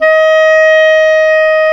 WND CLAR2 1N.wav